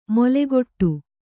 ♪ molegoṭṭu